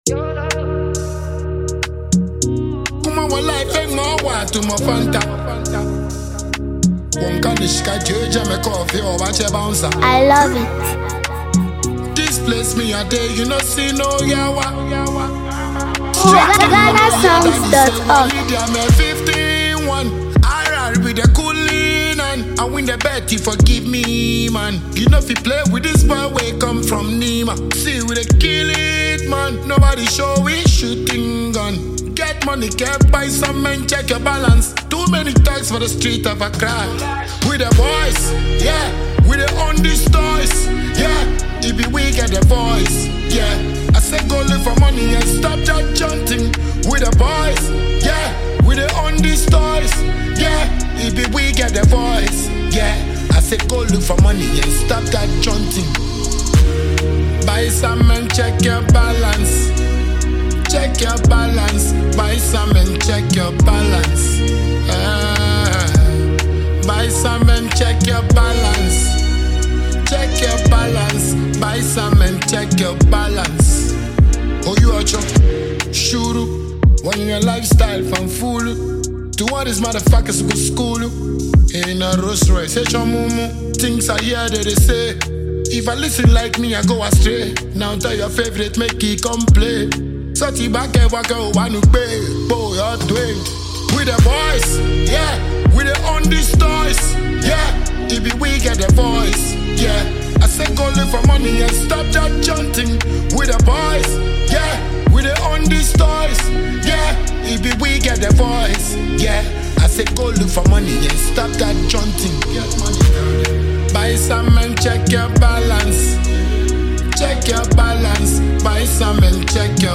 Ghanaian dancehall
With honest lyrics and a confident tone